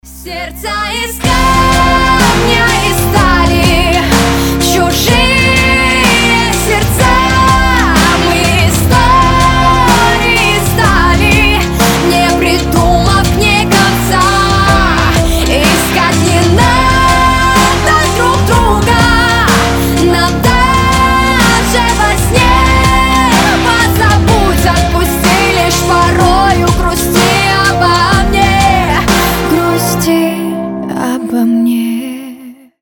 • Качество: 320, Stereo
женский вокал
Pop Rock